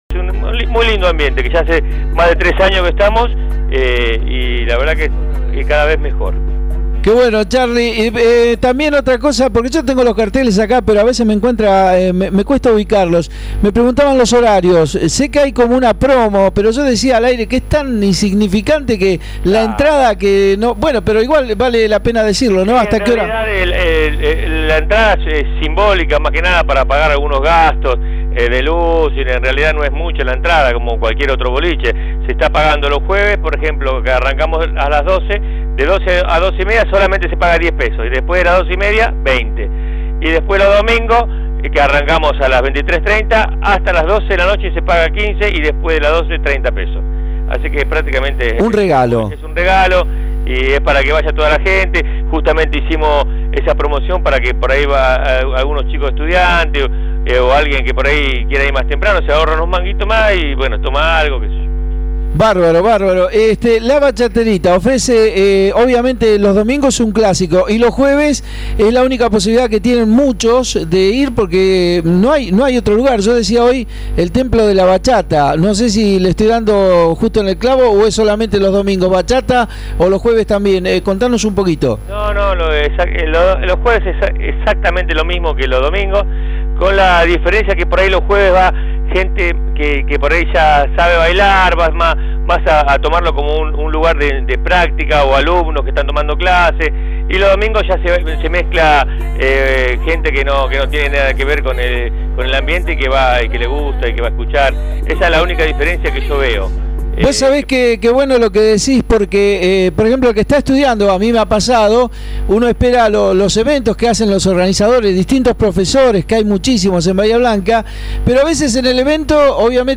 HOY EN EL PROGRAMA DE BACHATA YSALSA RADIO CHARLAMOS VIA TELEFONICA